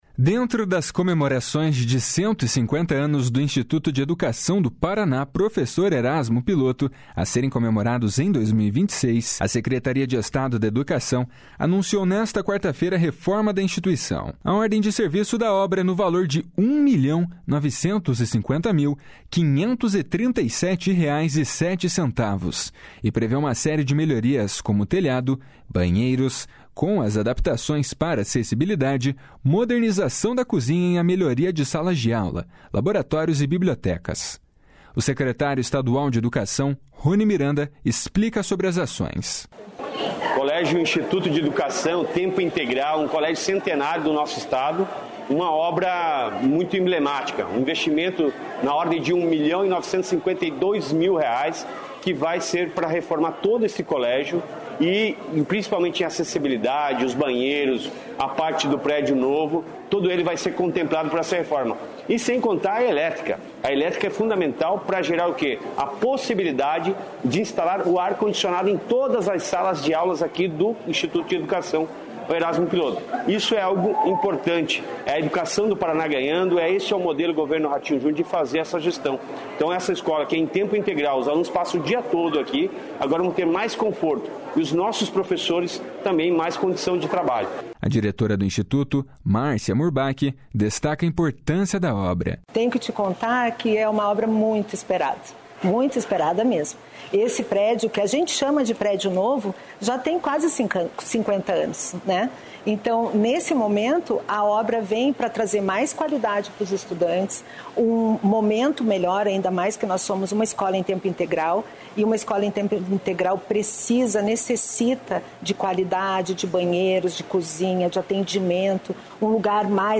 O secretário estadual de Educação, Roni Miranda, explica sobre as ações.